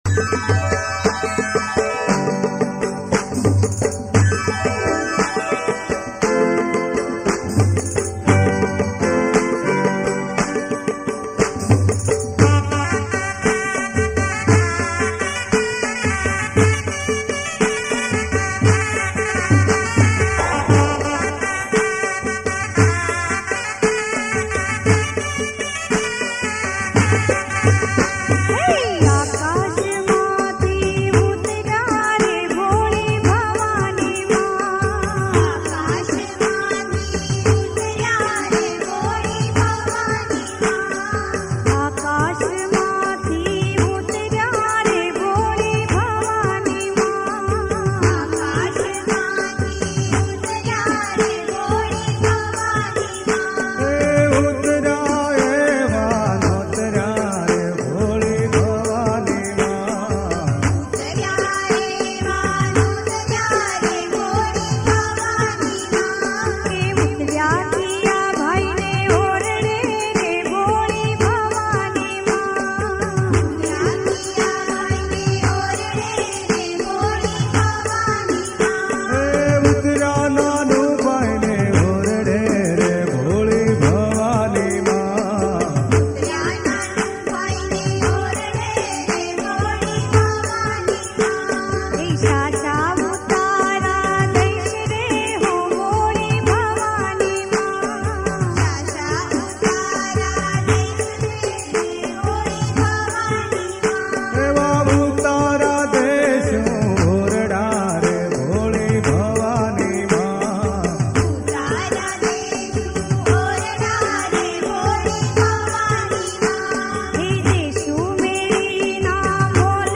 ગીત સંગીત ગરબા - Garba
Traditional Hit Navratri Garba Song.